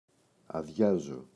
αδειάζω [aꞋðʝazo]